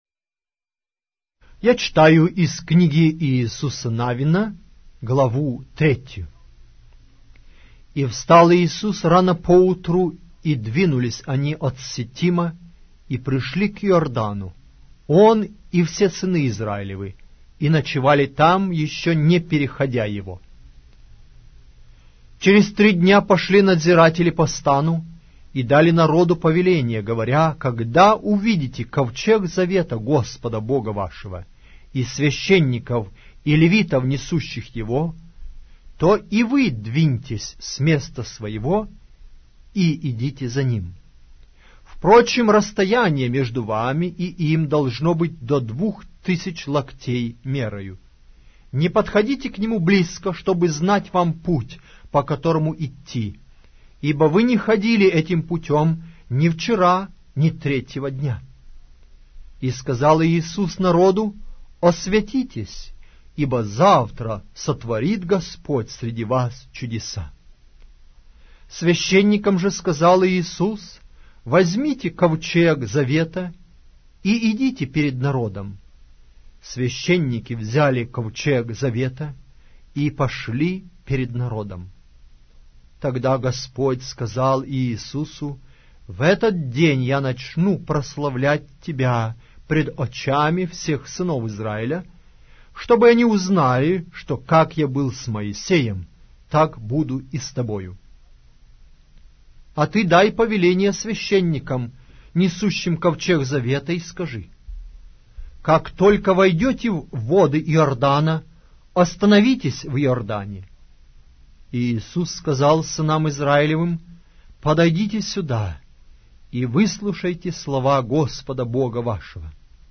Аудиокнига: Иисус Навин